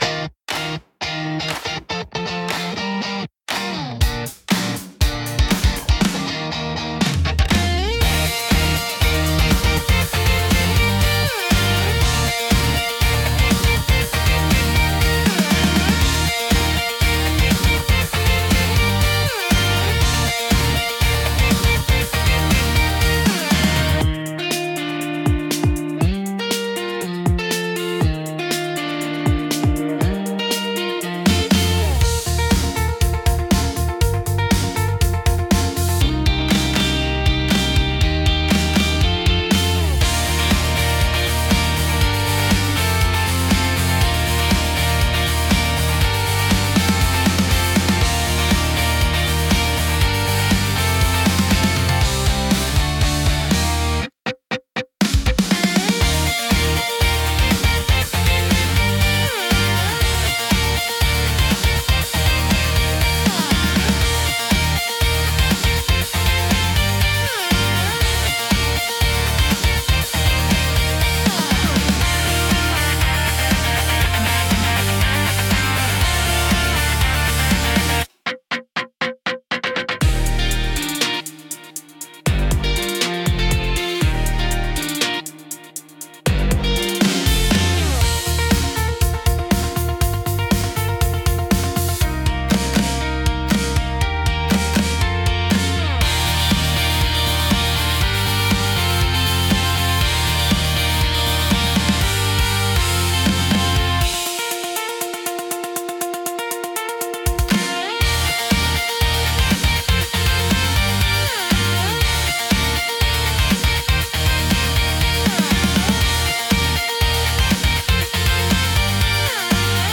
感情の高まりやポジティブな気分を引き出しつつ、テンポ良く軽快なシーンを盛り上げる用途が多いです。